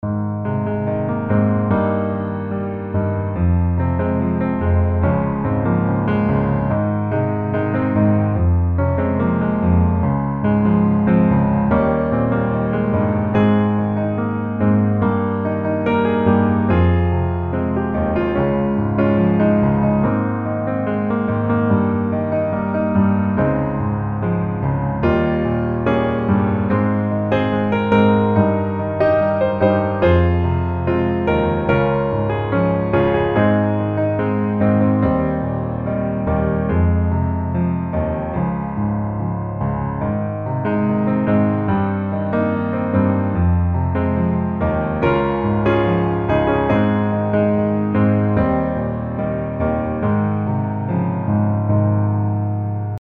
Ab大調